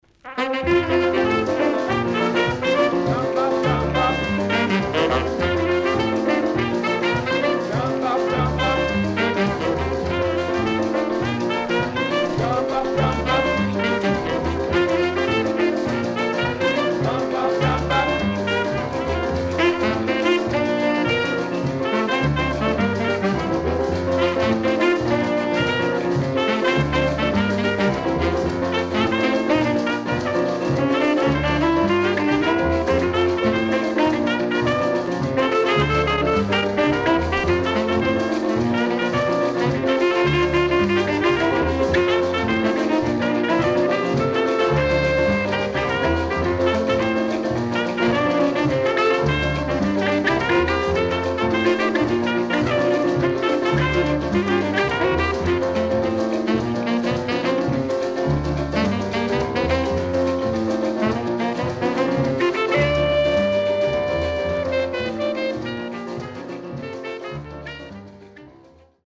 BOLERO, MAMBO,CAYPSO等の良曲多数収録。
SIDE B:所々チリノイズ入ります。